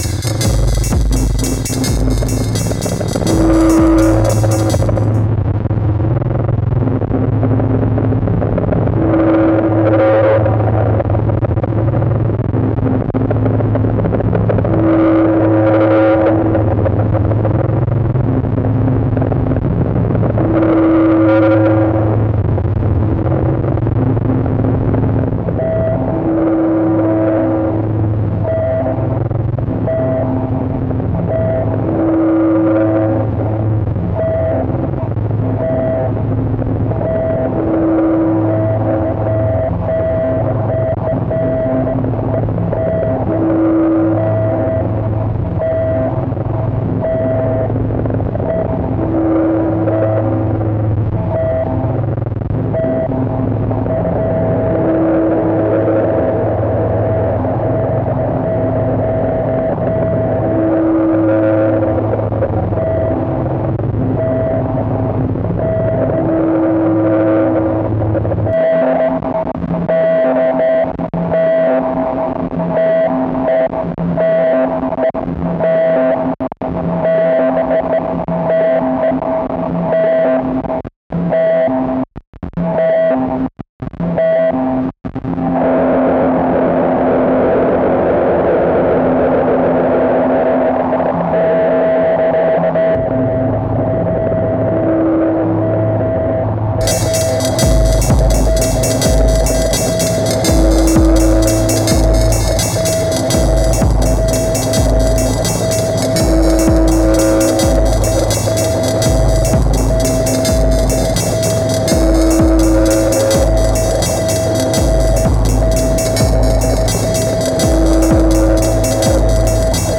snoozy bus ride in the black snow and sparkling headlights. vcv rack patch as well. i don't know about the drums on this one but they might convey the idea there maybe.